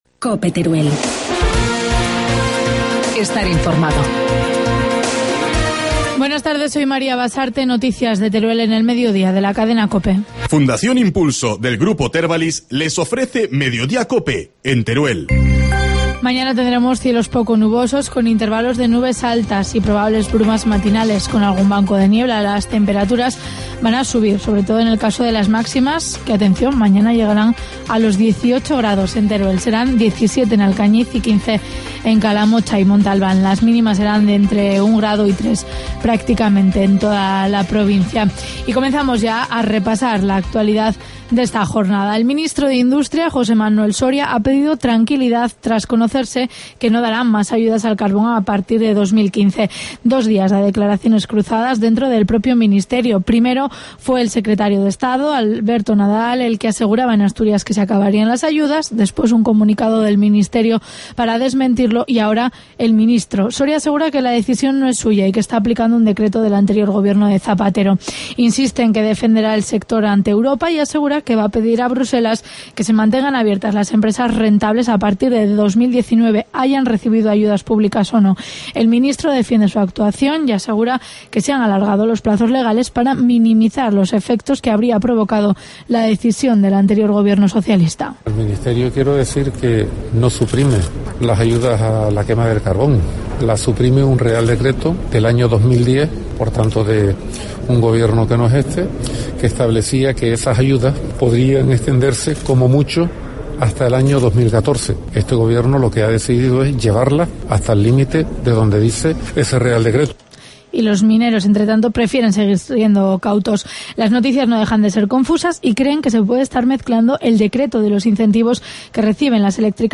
Informativo mediodía, martes 29 de enero